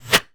pgs/Assets/Audio/Guns_Weapons/Bullets/bullet_flyby_fast_01.wav at master
bullet_flyby_fast_01.wav